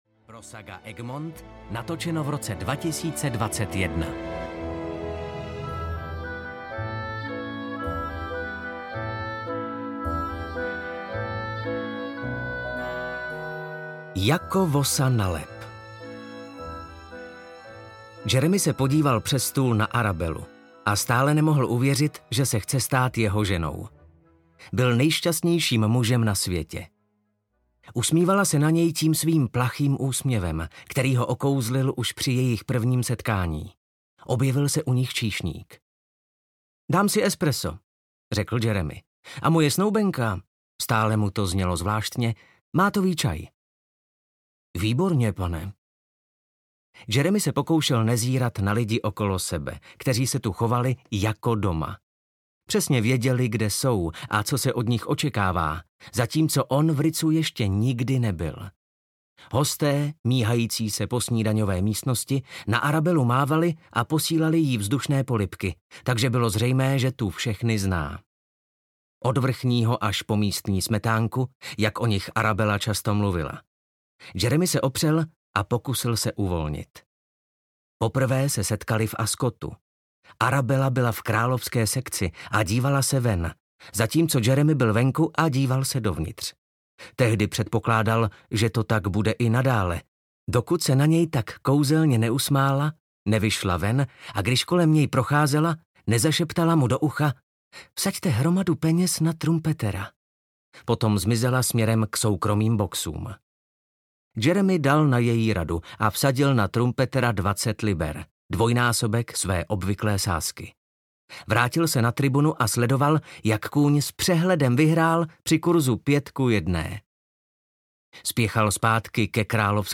A jak to bylo dál...? audiokniha
Ukázka z knihy